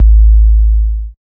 90 808 KIK-L.wav